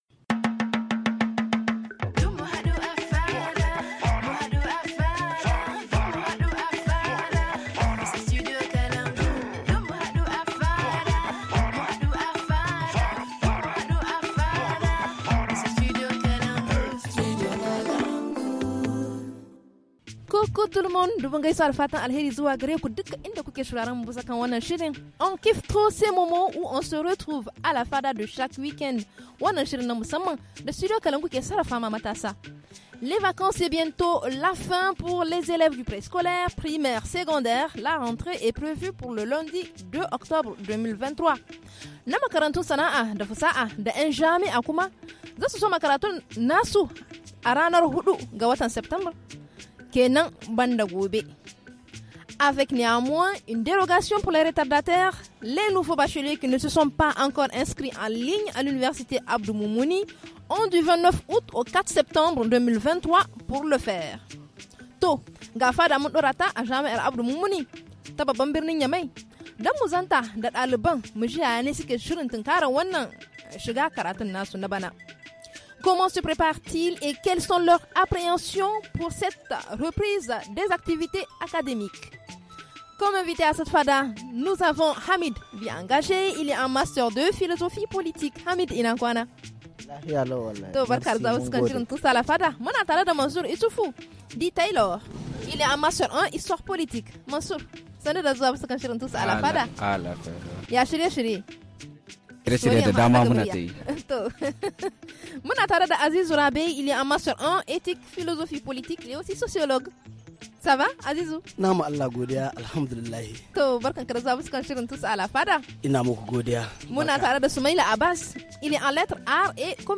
A la fada installée sur le campus de l’université de Niamey s’intéresse à l’état d’esprit de ces étudiants à quelques jours de la reprise des activités académiques.